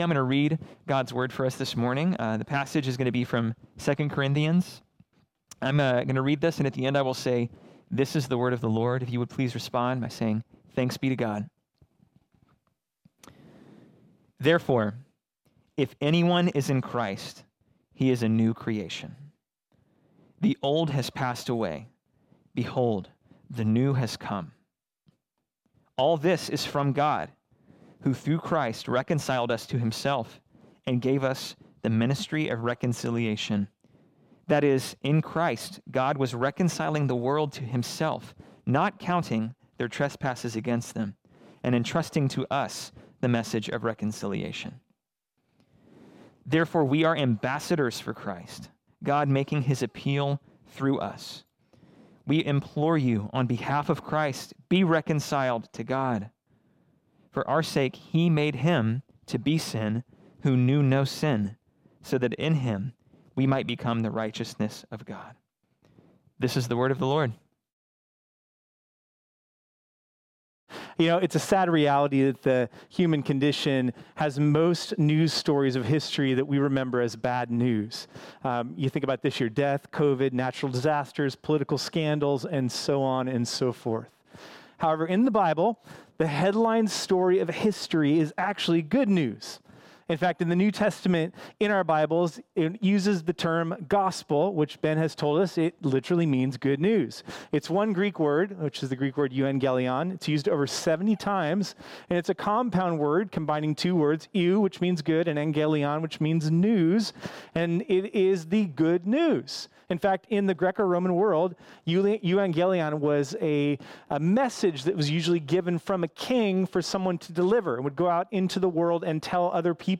This sermon was originally preached on Sunday, January 3, 2021.